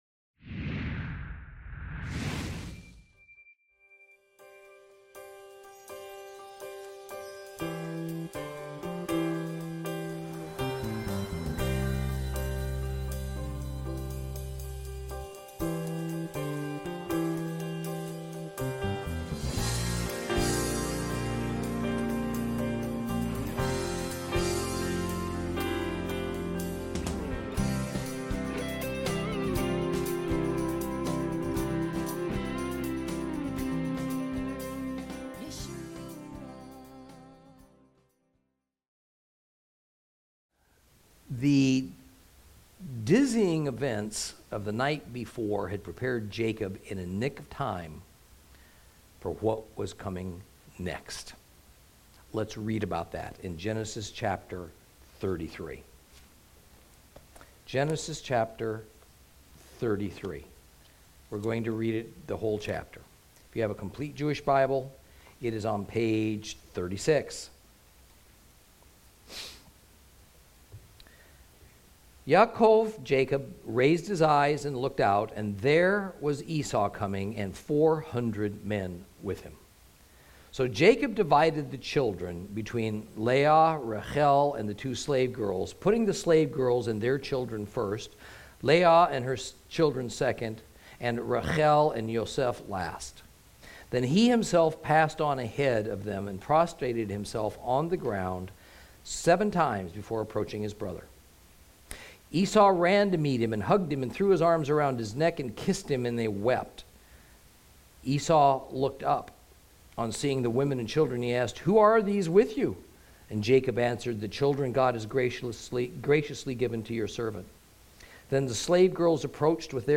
Lesson 31 Ch33 Ch34 - Torah Class